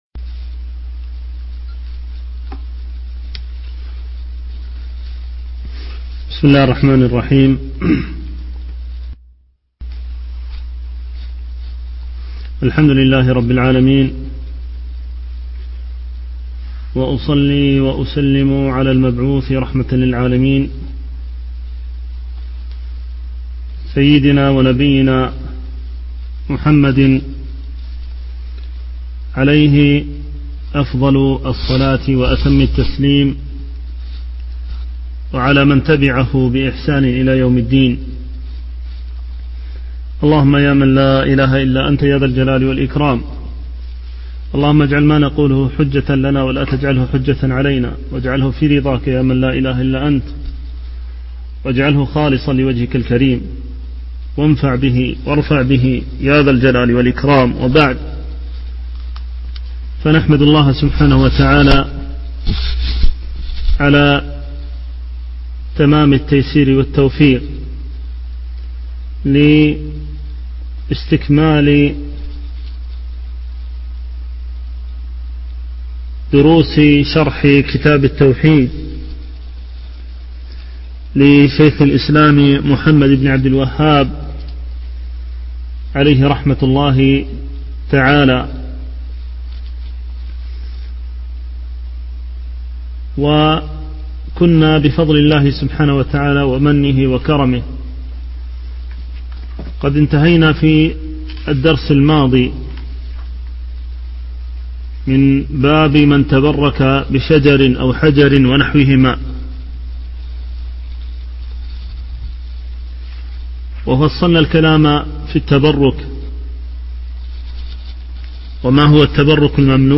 ملاحظة: الدرس الحادي عشر فيه انقطاع لمدة دقيقتين تقريبا من 42:35د حتى 44:35د